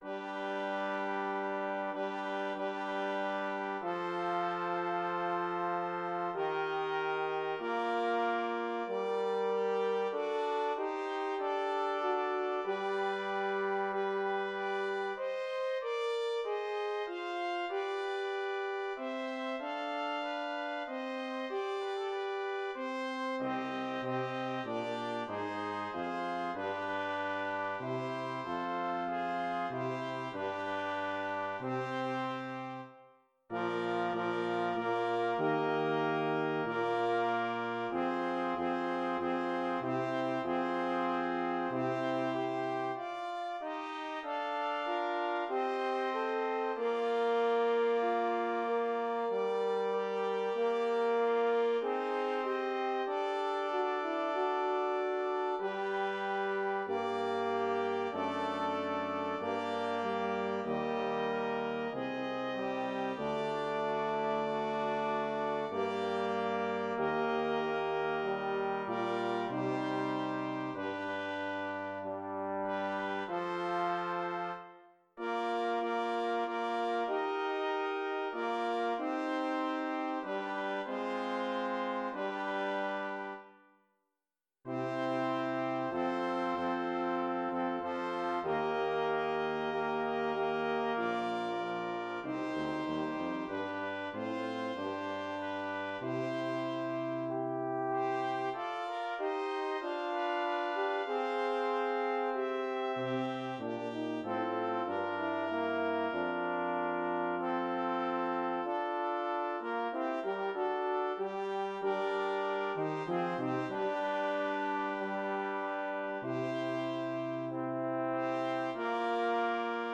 Synthesized Performance *#630845
Performers MIDI Copyright Creative Commons Attribution-ShareAlike 4.0 [ tag / del ] Misc.